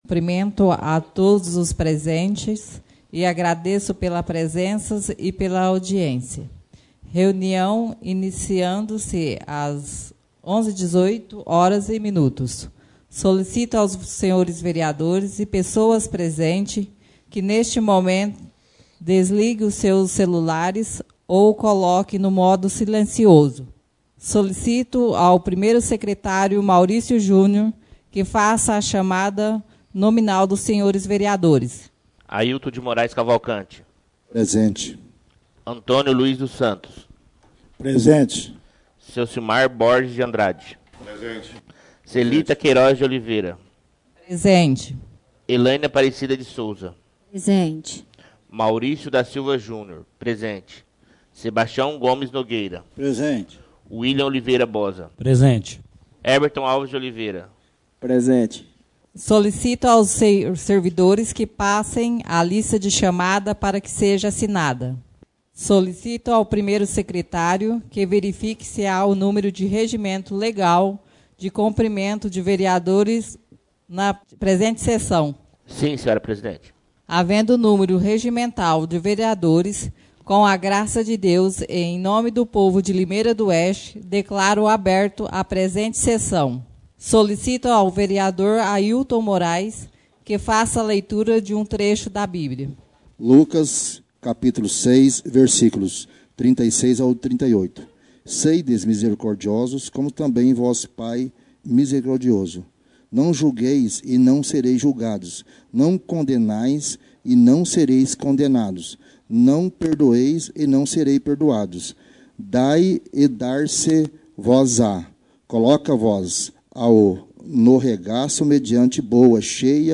Reuniões Extraordinárias